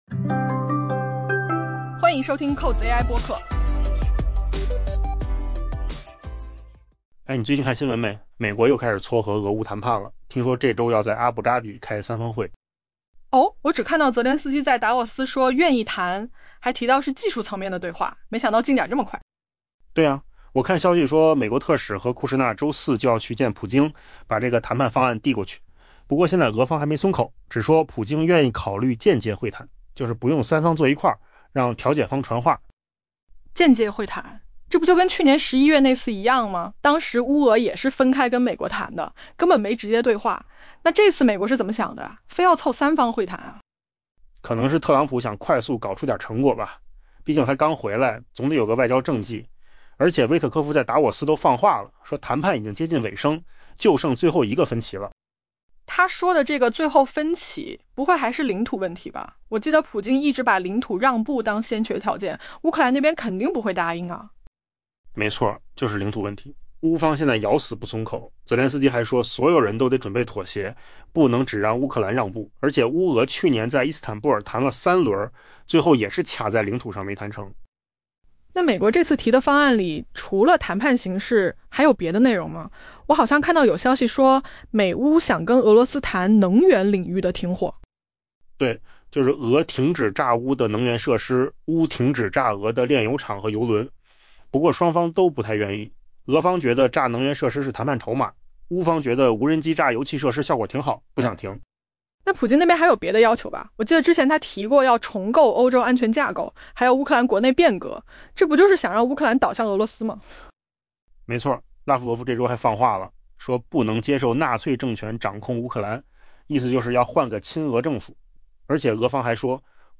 AI 播客：换个方式听新闻 下载 mp3 音频由扣子空间生成 据知情人士透露，美国正推动乌克兰与俄罗斯于本周晚些时候前往阿联酋阿布扎比，举行三方谈判，商讨可能达成的结束俄乌冲突的相关协议。